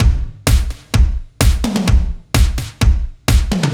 Index of /musicradar/french-house-chillout-samples/128bpm/Beats
FHC_BeatB_128-03_KikSnrTom.wav